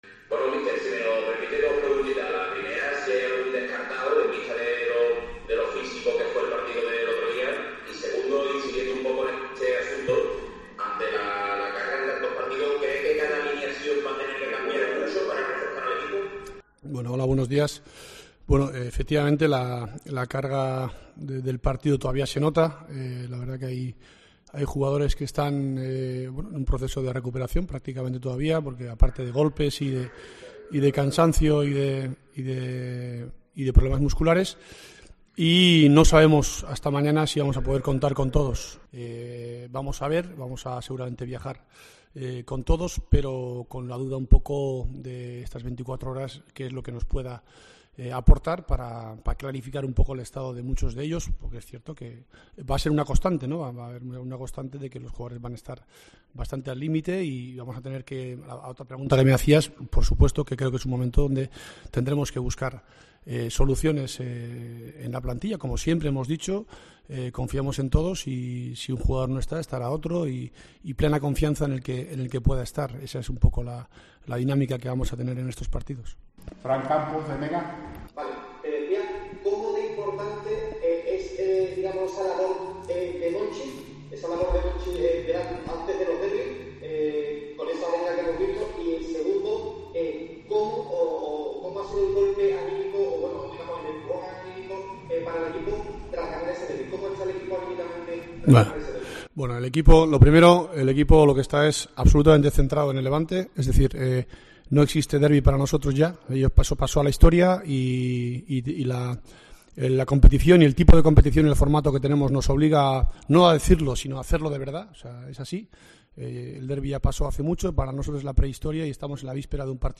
LOPETEGUI, EN RUEDA DE PRENSA ANTES DEL LEVANTE-SEVILLA